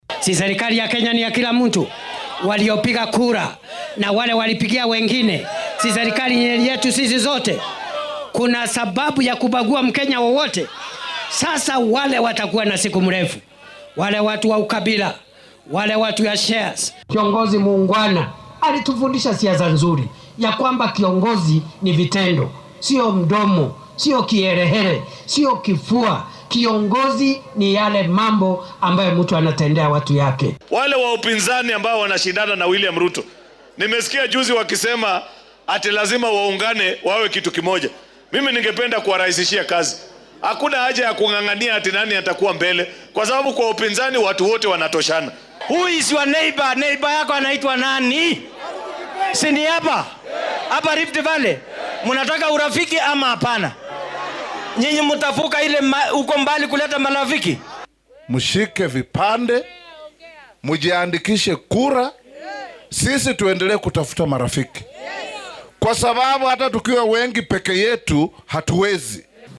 Madaxweyne ku xigeenka dalka Kithure Kindiki ayaa ku eedeeyay madaxda mucaaradka inay abuuraan kala qaybsanaan, nacayb iyo qabyaalad, isagoo sheegay inay taasi meesha ka saarayso guulihii laga gaaray midnimada iyo horumarinta dalka. Isagoo ka hadlayay deegaanka Othaya ee ismaamulka Nyeri oo ay ka socotay ka qeyb qaadashada xoojinta dhaqaalaha ee SACCOS iyo ganacsatada yar yar ayuu ku booriyay mucaaradka inay ku deydaan madaxweynihii saddexaad ee Kenya Mwai Kibaki sida naf-hurnimada leh uu dalka ugu adeegey.